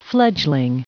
Prononciation du mot fledgling en anglais (fichier audio)
Prononciation du mot : fledgling